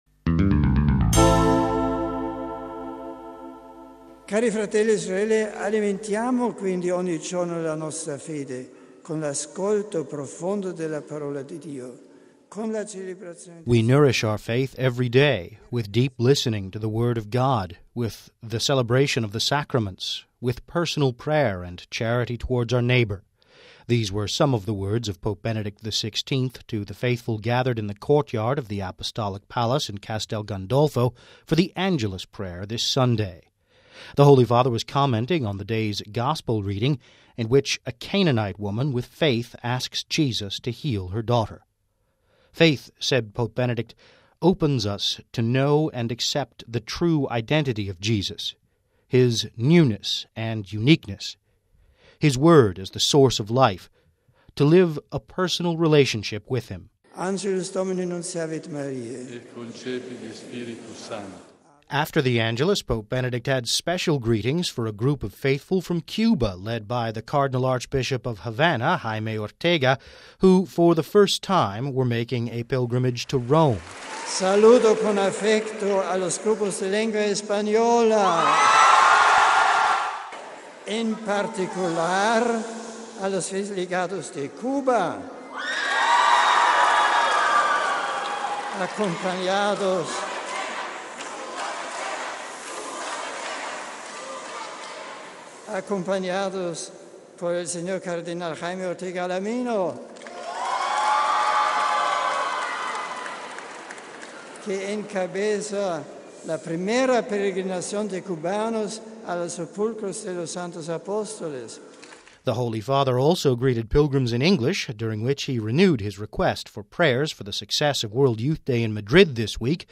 These were some of the words of Pope Benedict XVI to the faithful gathered in the courtyard of the Apostolic Palace in Castel Gandolfo, for the Angelus prayer this Sunday. The Holy Father was commenting on the day’s Gospel reading, in which a Canaanite woman with faith asks Jesus to heal her daughter.